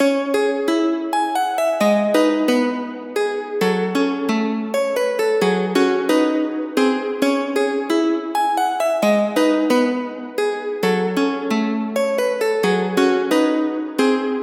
Tag: 133 bpm Trap Loops Guitar Acoustic Loops 2.43 MB wav Key : C